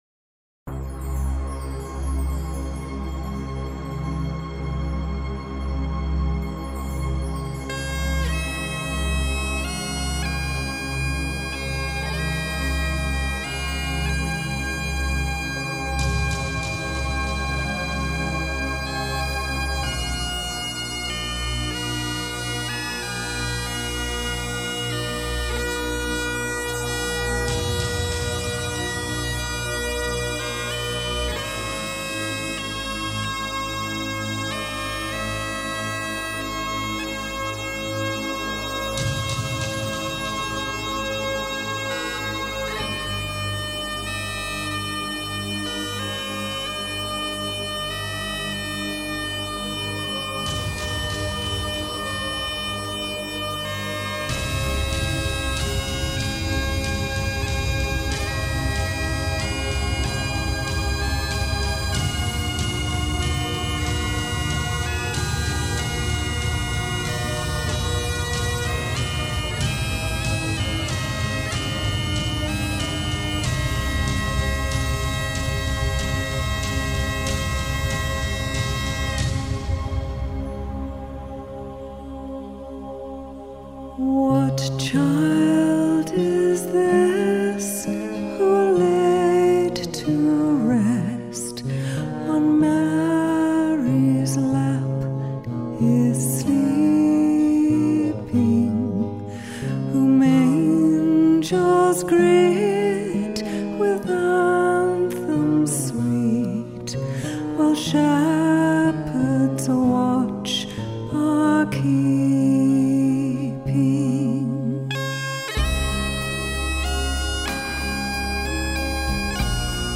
Demo tracks recorded & mixed in Vancouver, BC Canada at:
Studio Recorded November, 2017